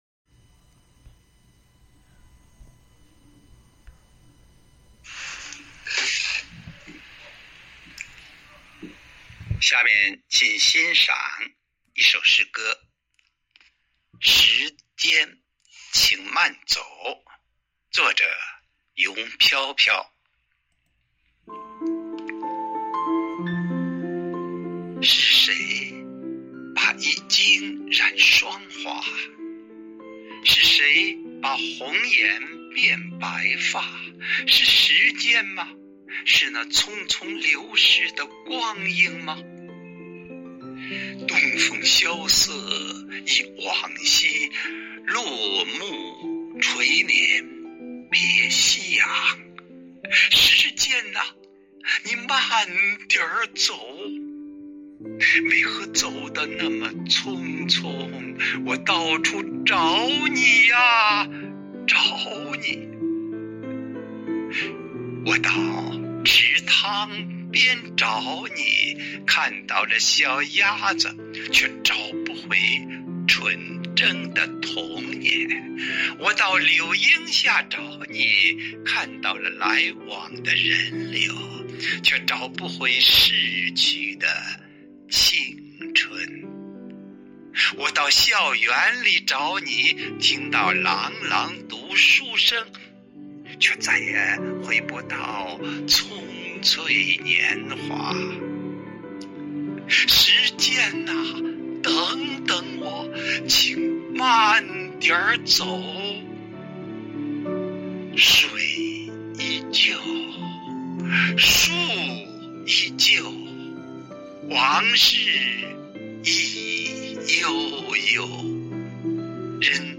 【诗海撷英】